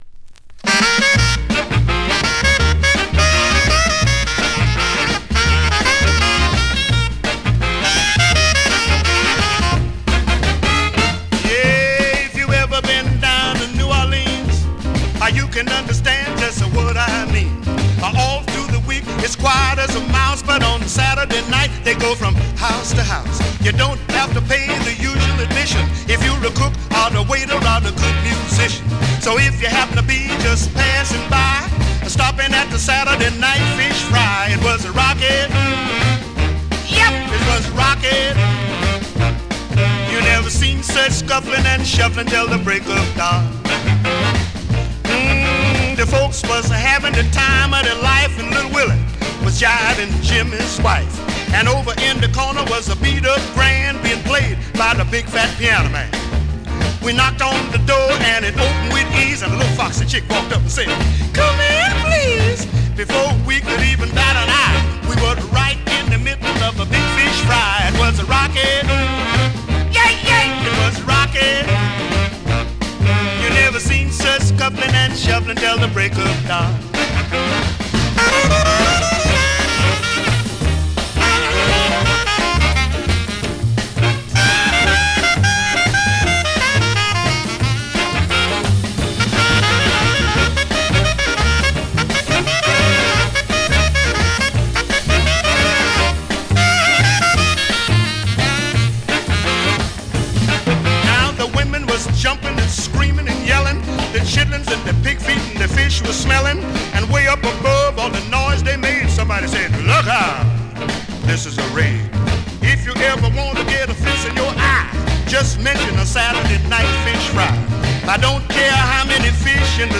Vinyl has a few light marks plays great .
Great classic up-tempo Rnb / Mod dancer .